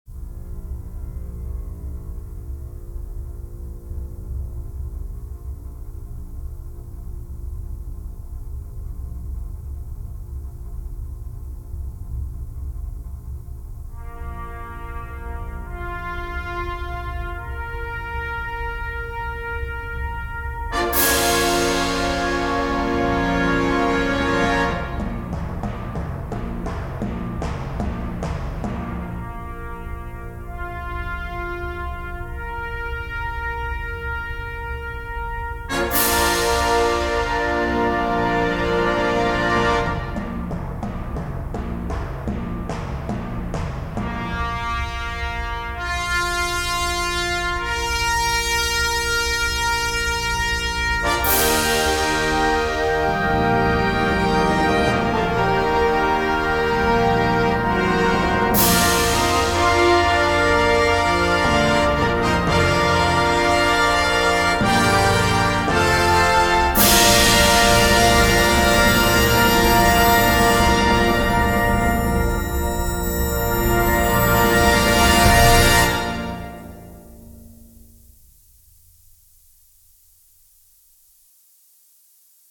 KeyBb Major
CategoryConcert Band
Bass Trombone
Euphonium
Timpani
Cymbals / Bass Drum